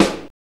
WHITE NOISE.wav